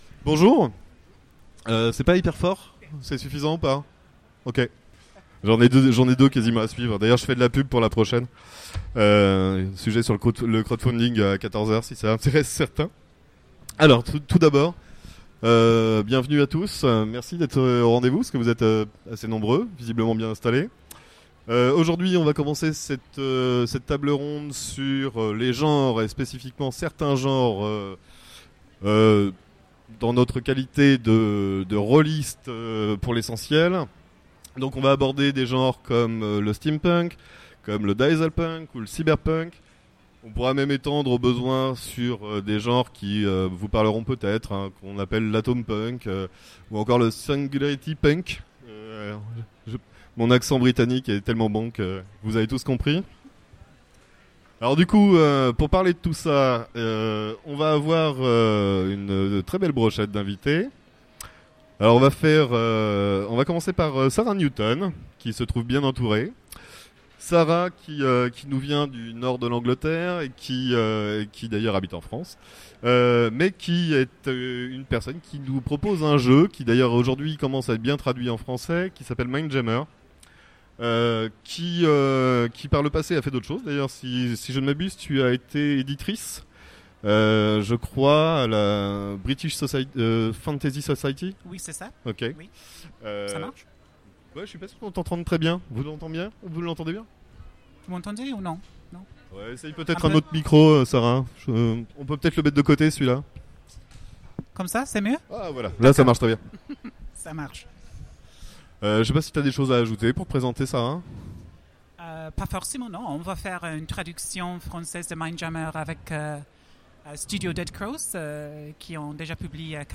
Utopiales 2016 : Conférence Les genres en JDR : quelles expériences nous permettent-ils ?